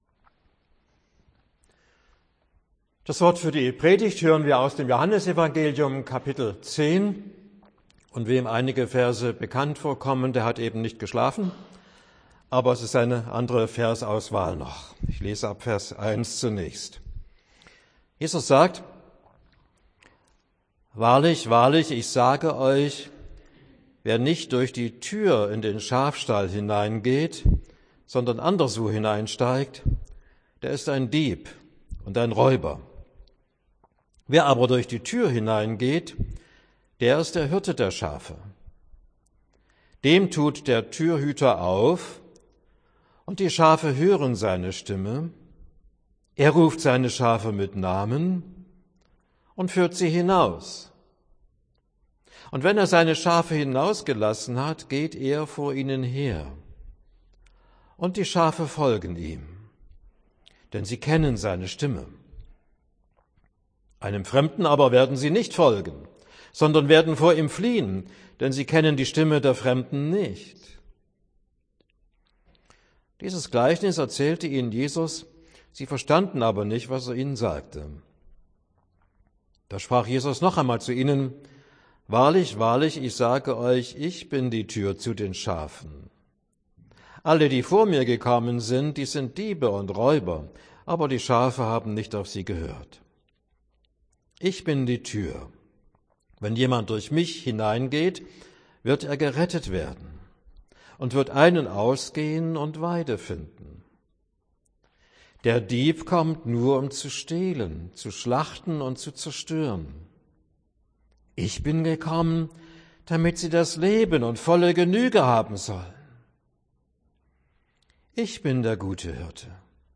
Predigt für den Sonntag Miserikordias Domini